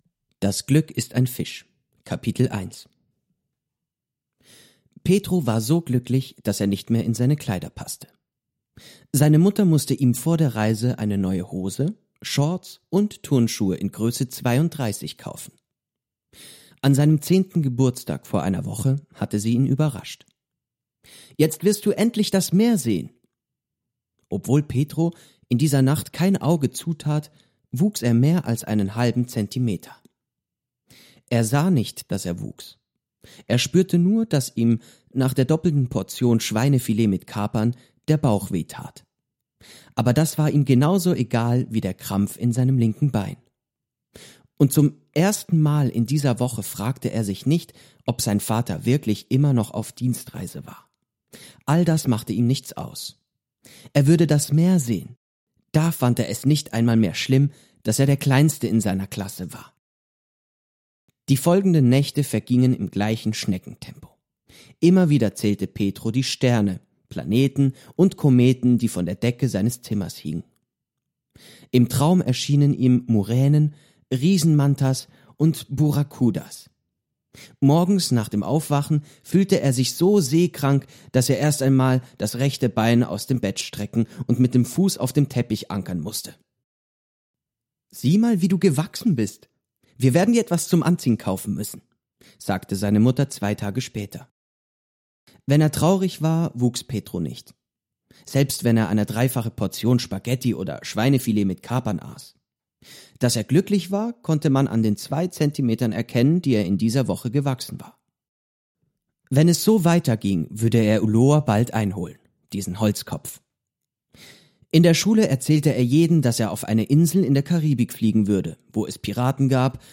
→ Mehr zum BuchWeitere Materialien zum Buch→ Kopiervorlage Minibook→ Lesung Deutsch Teil 1 (MP3)→ Lesung Deutsch Teil 2 (MP3)→ Angebot Leseanimation «BuchBesuch»